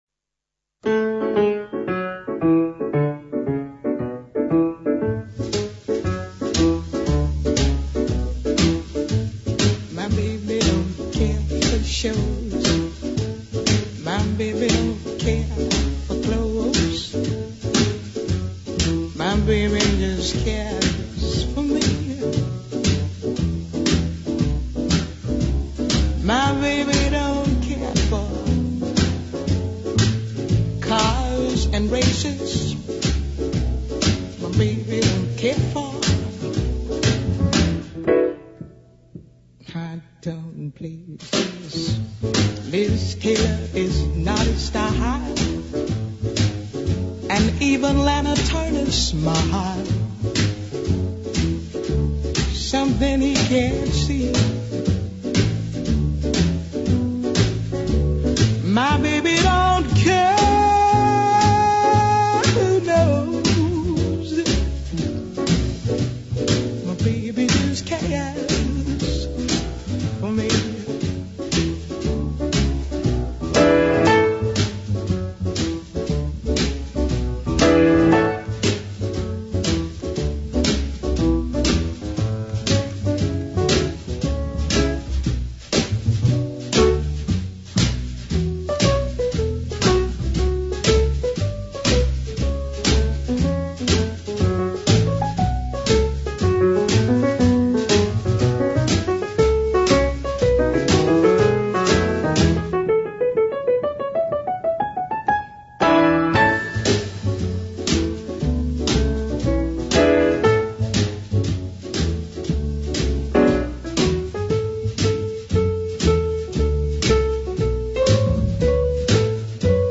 Descargar Audio no soportado Leímos fragmentos de algunos libros de la escritora norteamericana más lúcida, agridulce y ácida que hayamos leído hasta el momento.